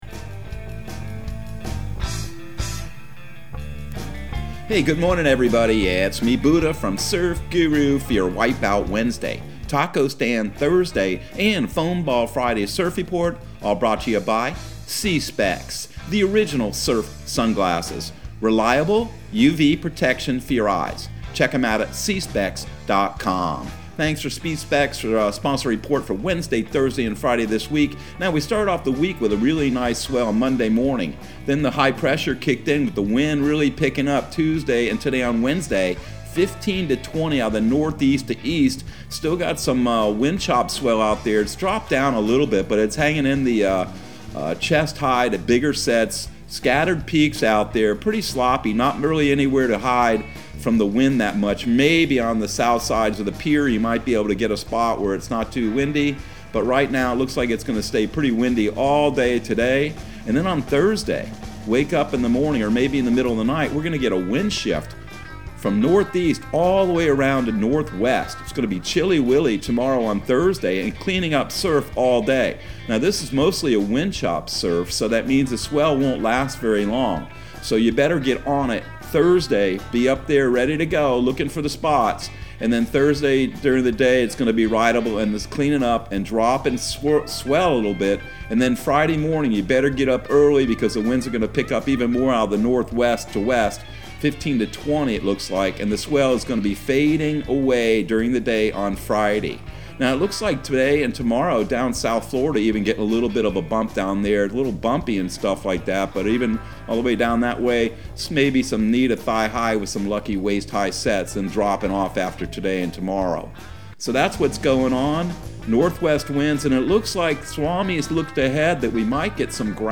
Surf Guru Surf Report and Forecast 01/12/2022 Audio surf report and surf forecast on January 12 for Central Florida and the Southeast.